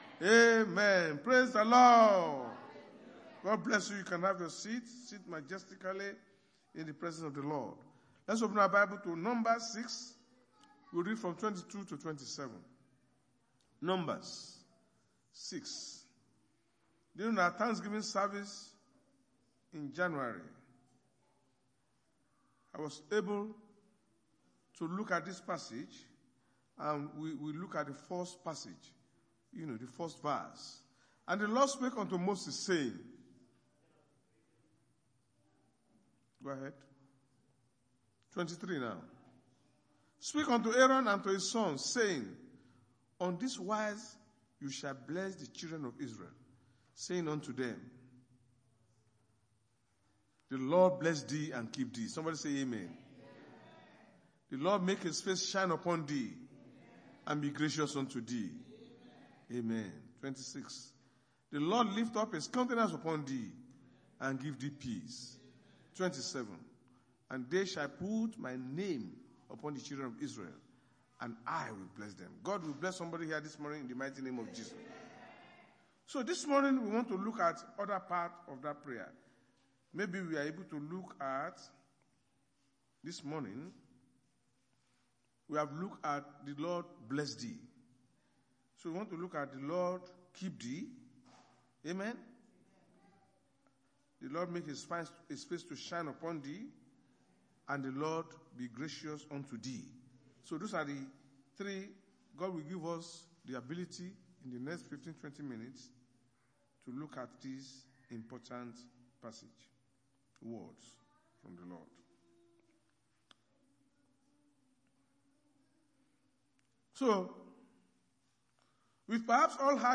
Sunday Sermon: The Lord Keep You
Service Type: Sunday Church Service